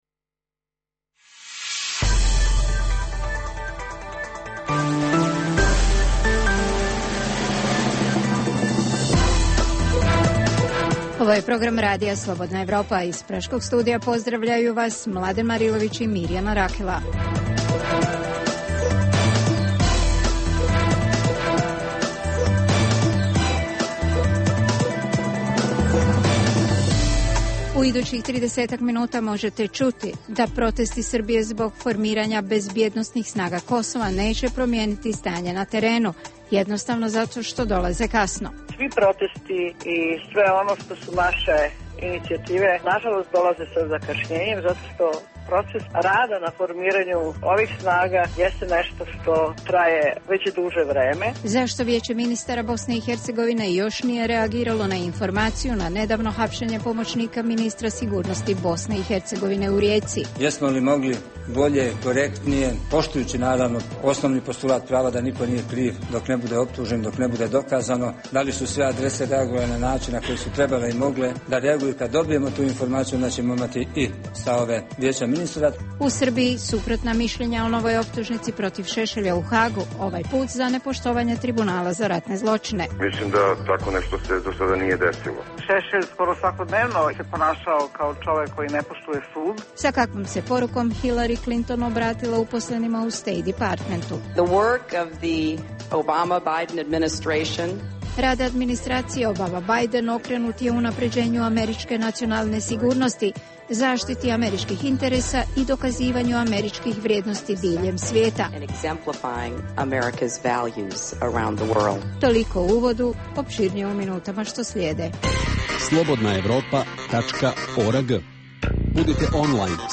Možete čuti i razgovor sa književnikom Vladimirom Pištalom, dobitnikom nagrade "NIN"-a za roman " Tesla, portret među maskama".